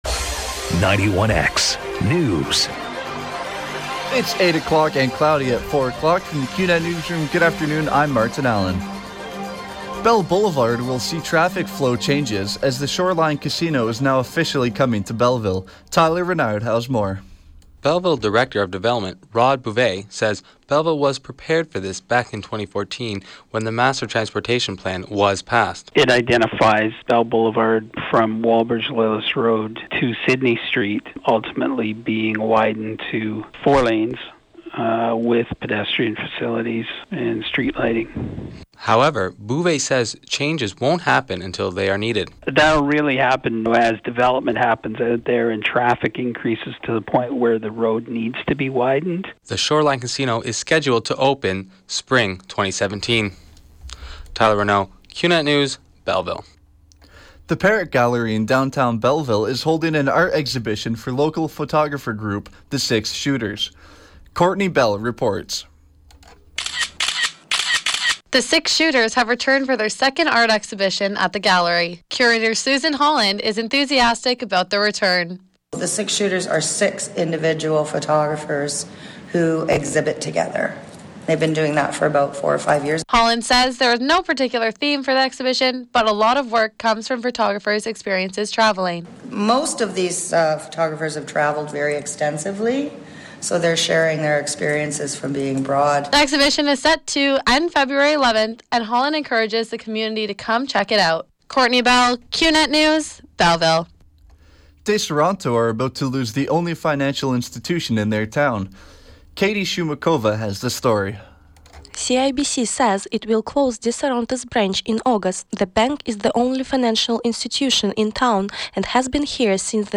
91X newscast – Wednesday, Feb. 3, 2016 – 4 p.m.